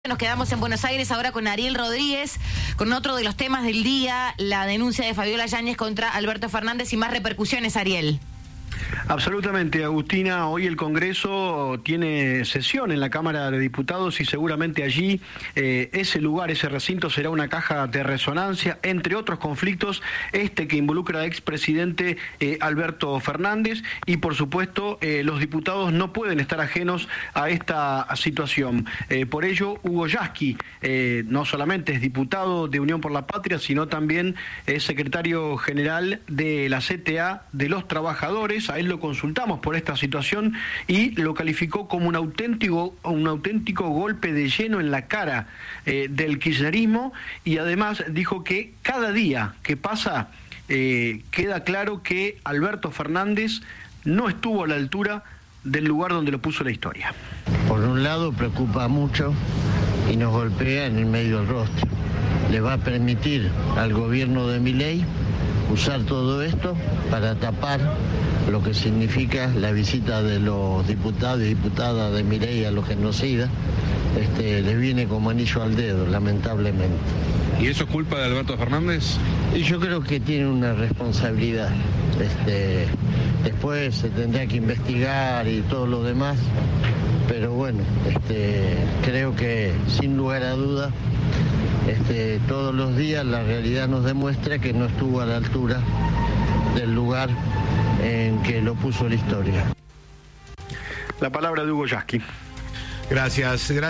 El diputado de Unión por la Patria y secretario general de la CTA habló con Cadena 3 y dijo que el expresidente "no estuvo a la altura del lugar donde lo puso la historia".
Informe